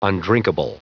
Prononciation du mot undrinkable en anglais (fichier audio)
Prononciation du mot : undrinkable